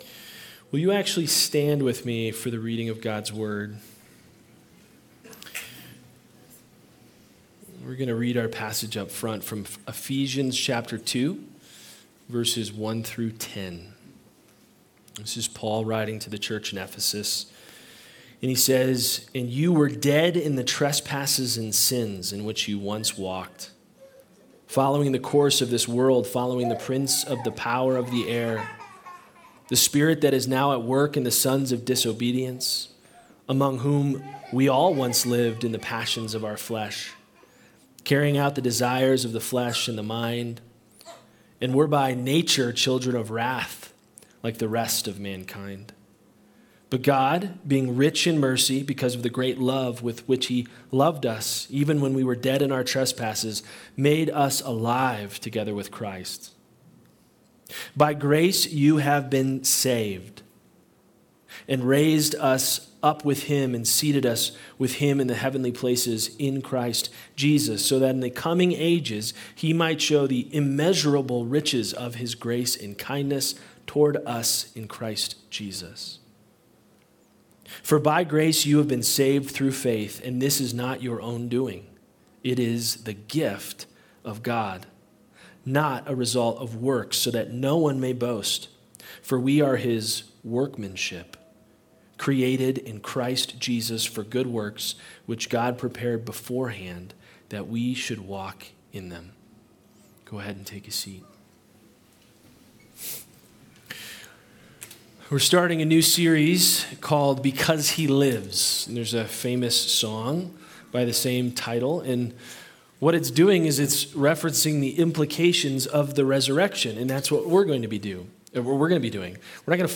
Sermons | Anchor Way Church
Anchor Way Sunday Service